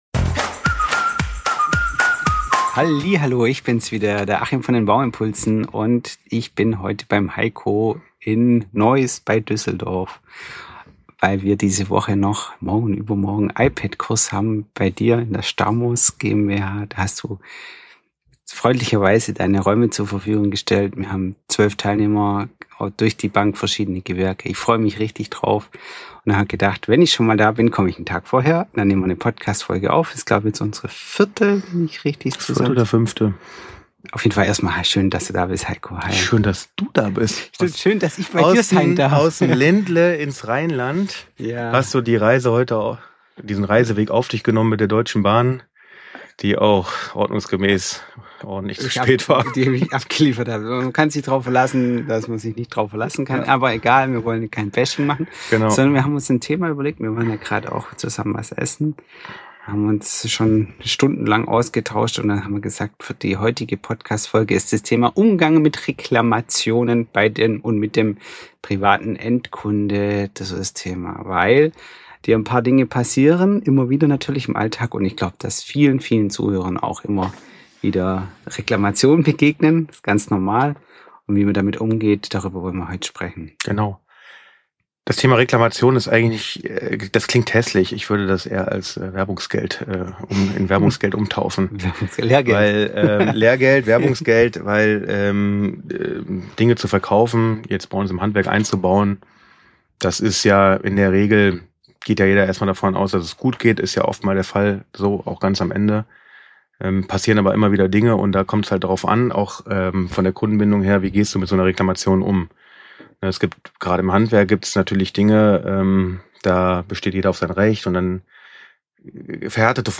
Ein ehrliches Gespräch mit vielen Praxistipps und Best Practices für Handwerksbetriebe – frisch aufgenommen Kurz vor dem iPad-Workshop in der Stamos GmbH.